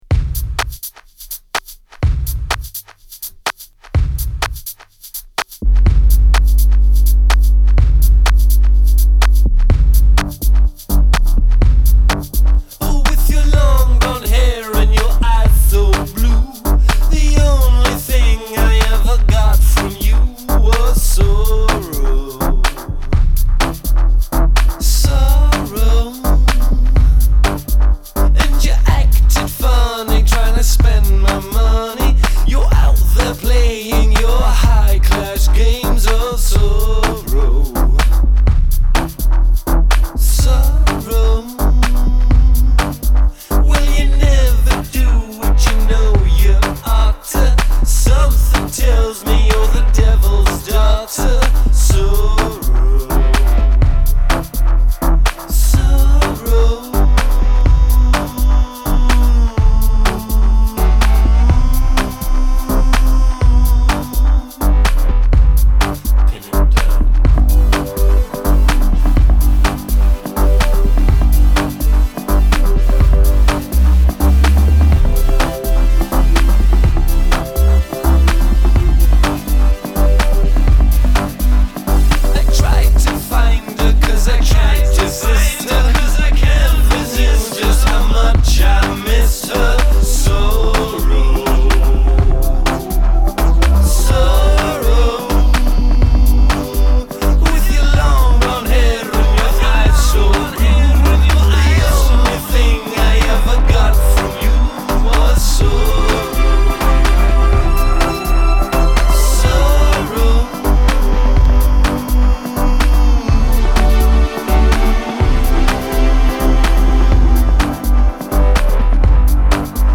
Scottish electro crooner